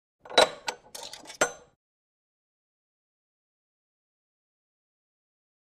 Coin Out Pay Phone | Sneak On The Lot
Coin Removed From Pay Phone Coin Return.